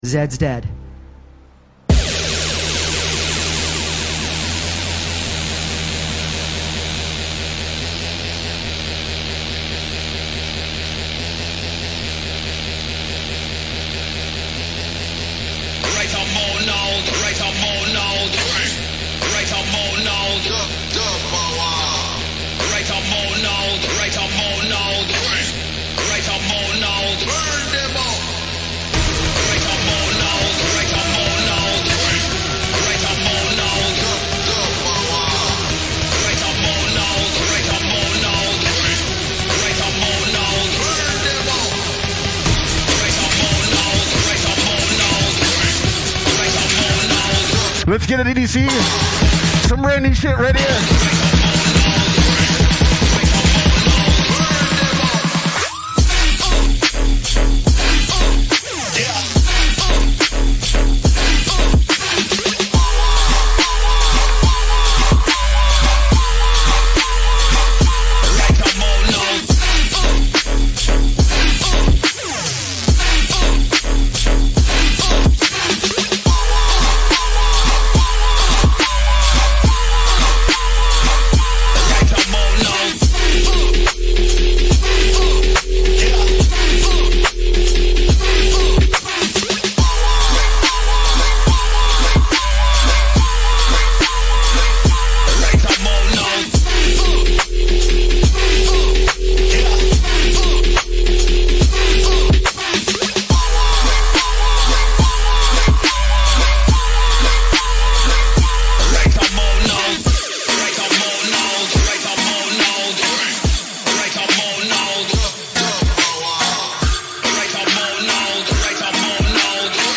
Quarantine Livestreams Genre: House